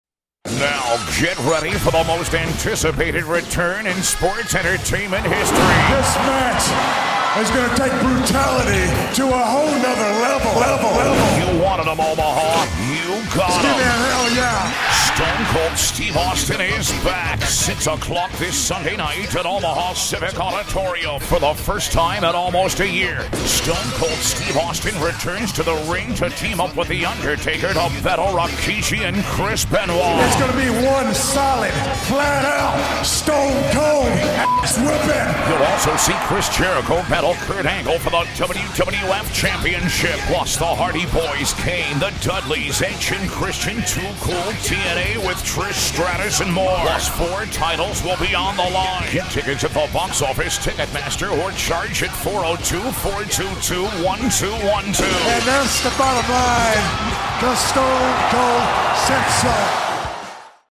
audio ad for a WWF event.